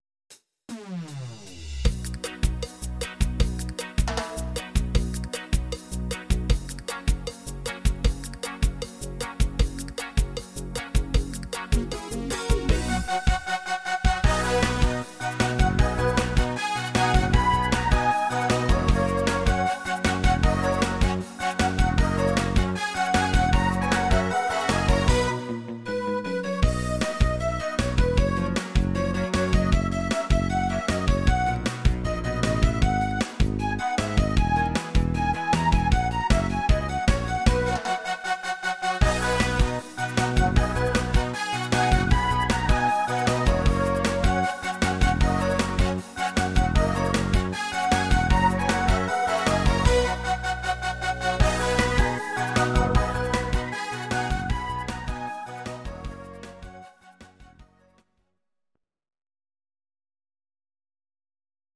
Audio Recordings based on Midi-files
Pop, Ital/French/Span, 2000s